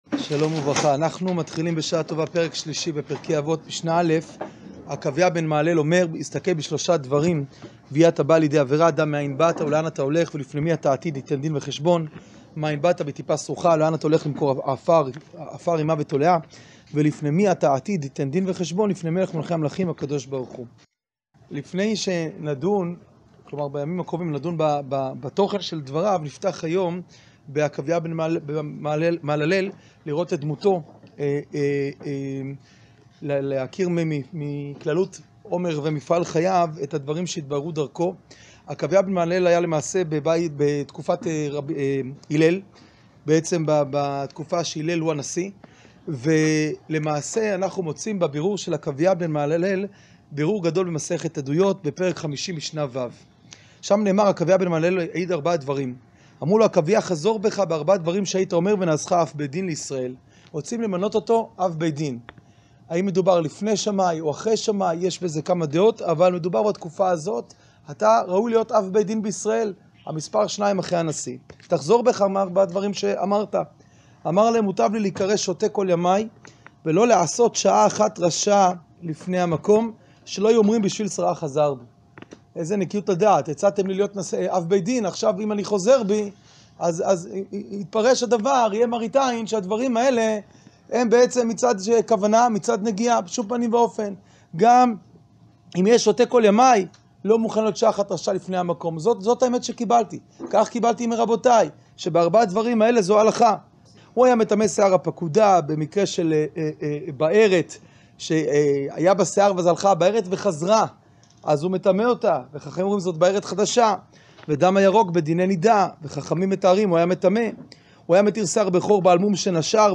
שיעור פרק ג משנה א